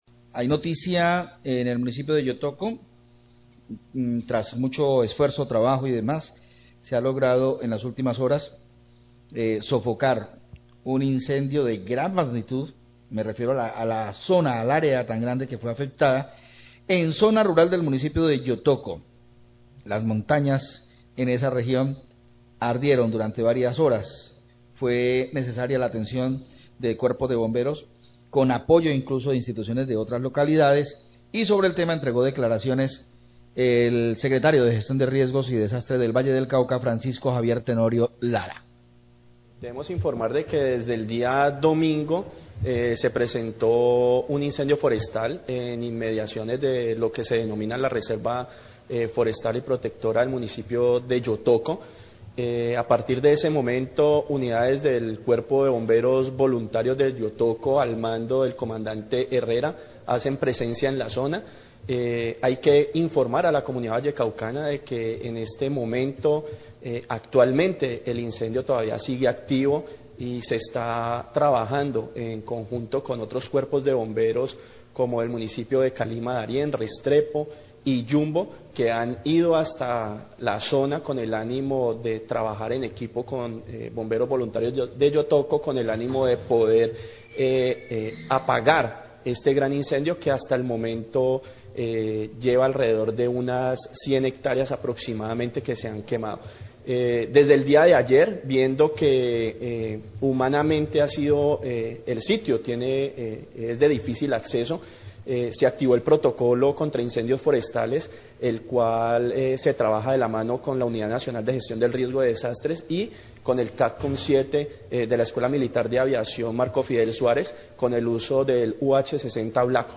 Srio Gestión Riesgo Valle habla de atención incendio en reserva forestal de Yotoco
Radio